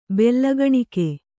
♪ bellagaṇike